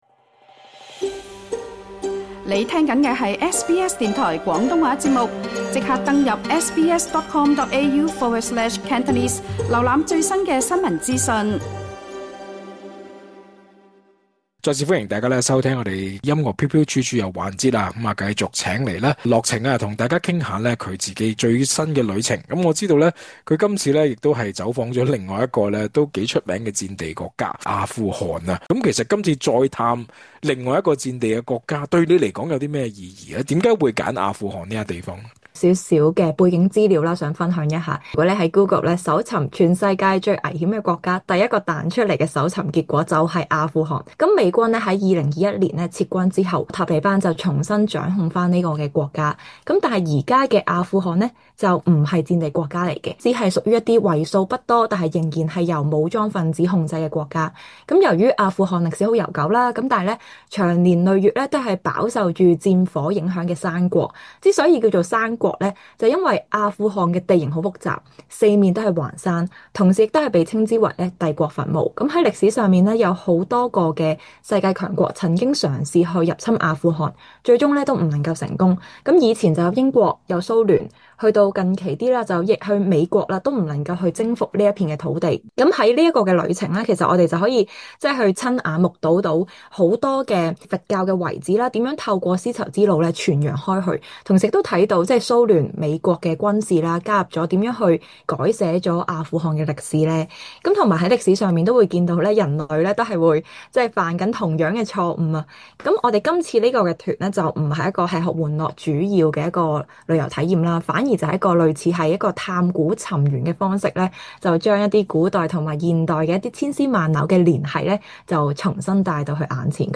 精彩內容請收聽完整訪問：